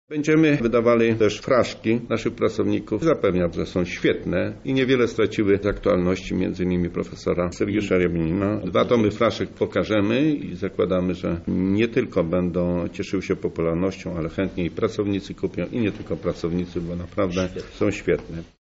Stanisław Michałowski, rektor UMCS